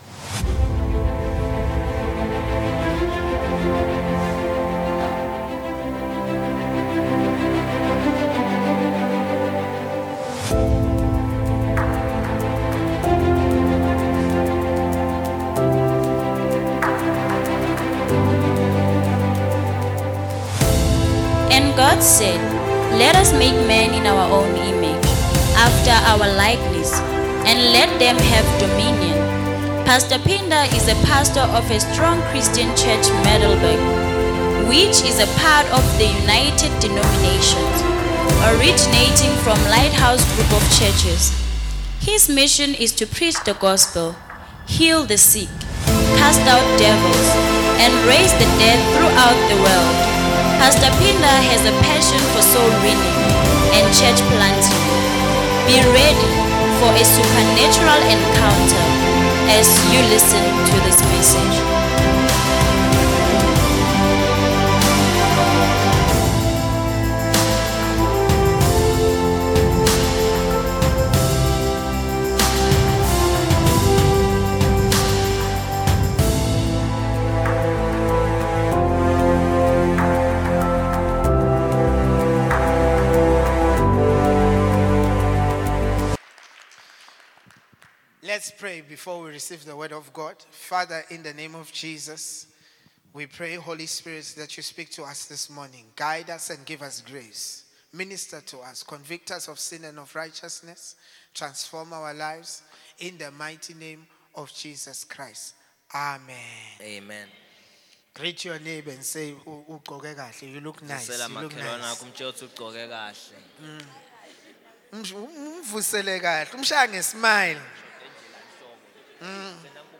Preached at the Divine Healing Centre during the Supernatural Encounter Service, 27th July 2025